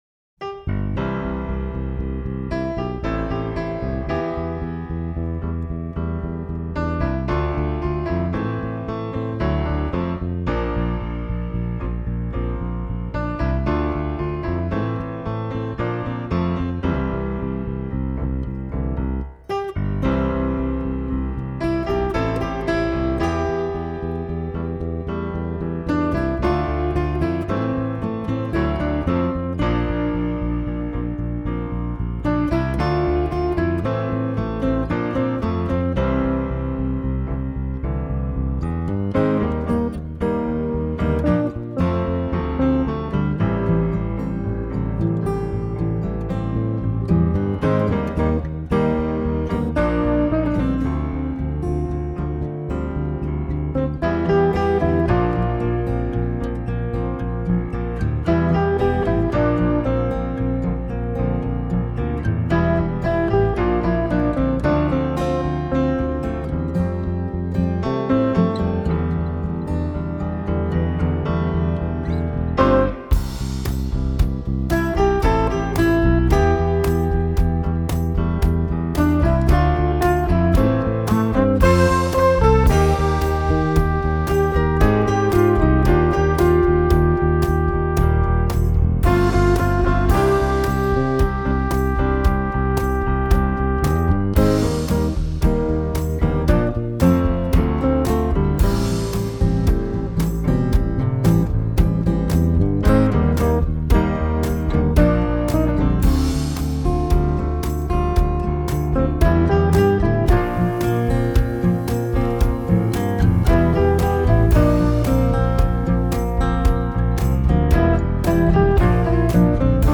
Trompete
Gitarren
Klavier
Bassgitarre
Perkussion